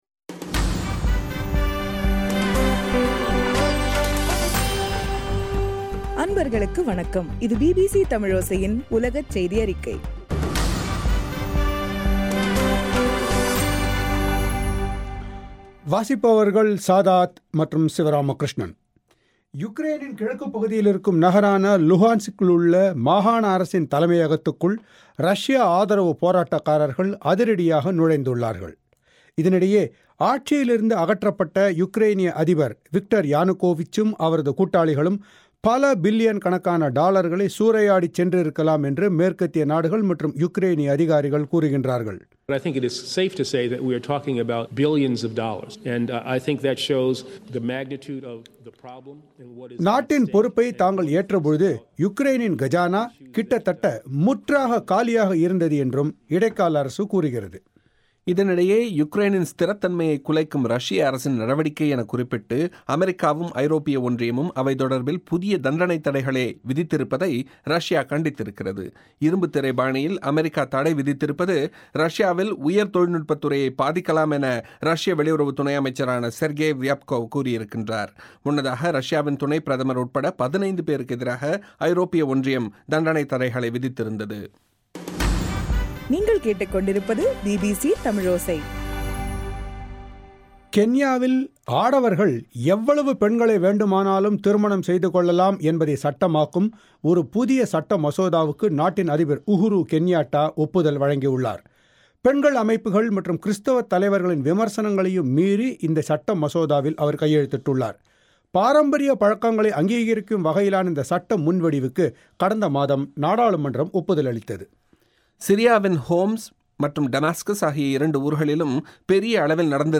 ஏப்ரல் 29 பிபிசி தமிழோசை உலகச் செய்தி அறிக்கை